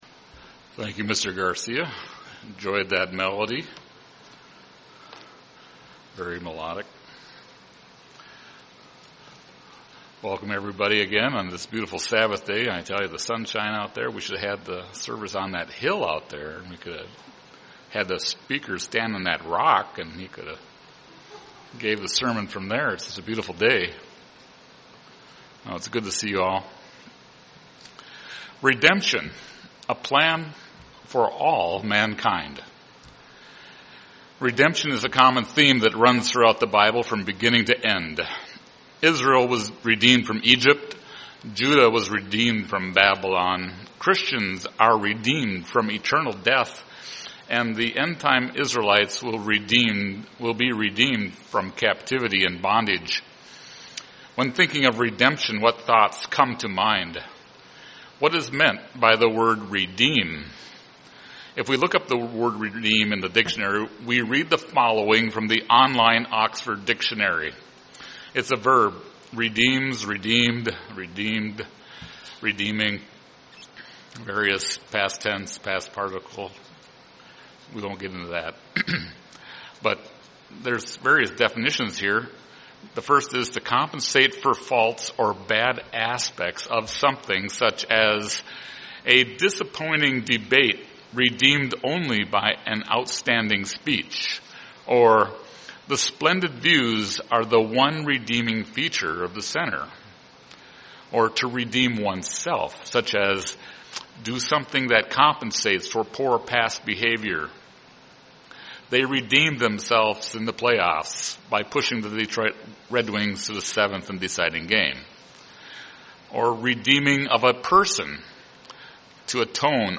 Given in Twin Cities, MN
UCG Sermon redemption Studying the bible?